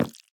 Minecraft Version Minecraft Version latest Latest Release | Latest Snapshot latest / assets / minecraft / sounds / block / sign / waxed_interact_fail2.ogg Compare With Compare With Latest Release | Latest Snapshot
waxed_interact_fail2.ogg